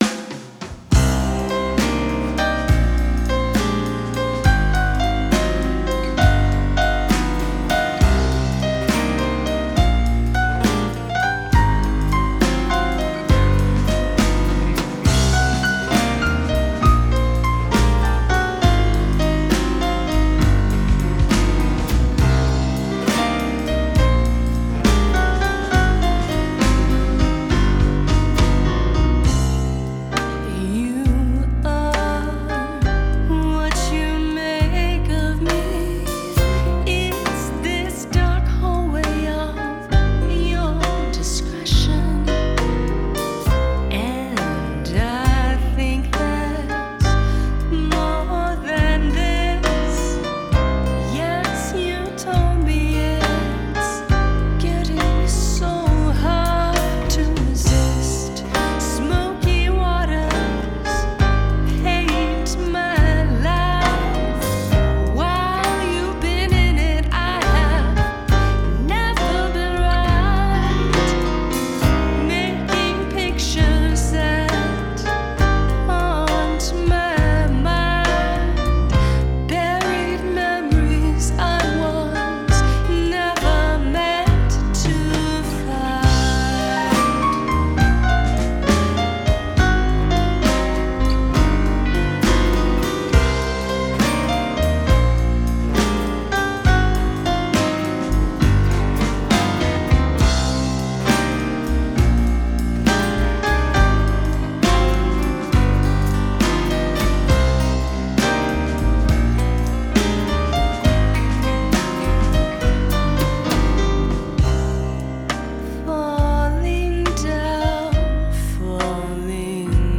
standup bass, piano, guitar, vocals
drums, percussion.
Genre: Jazz Vocals, Gipsy Swing